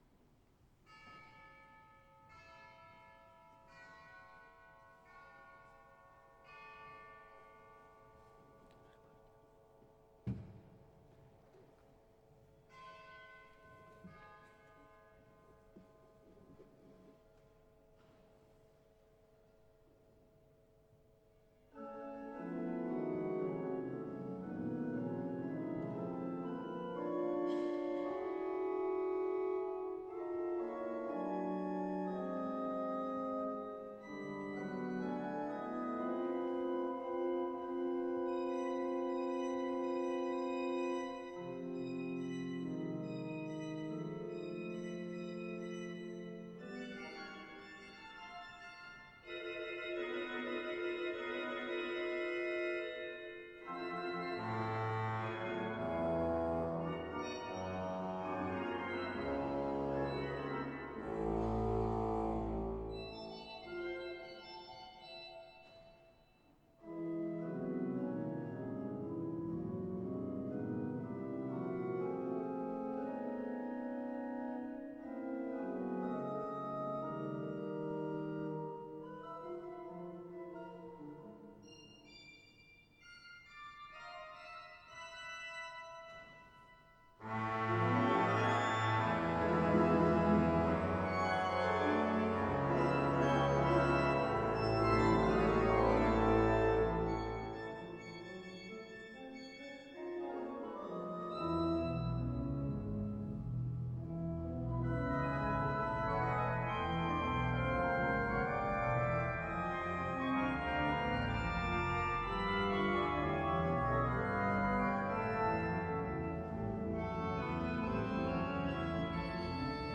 Velikost 3 manuály 59 rejstříků